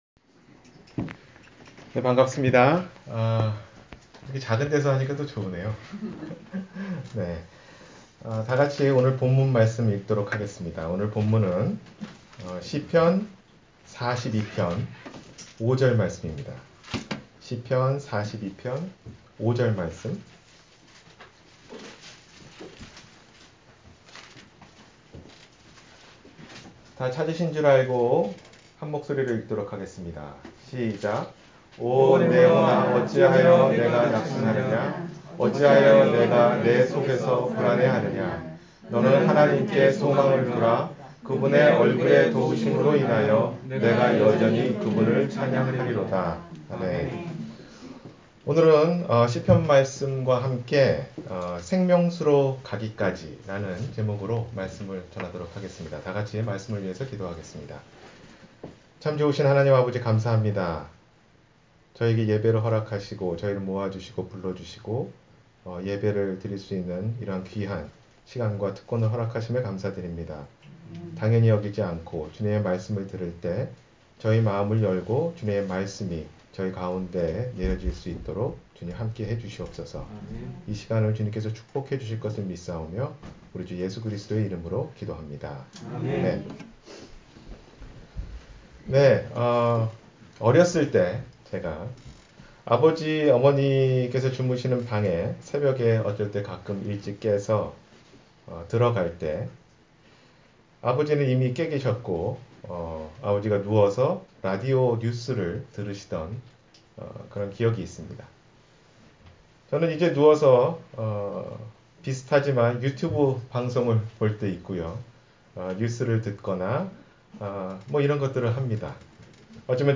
생명수로 가기까지-주일설교